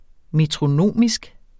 Udtale [ metʁoˈnoˀmisg ]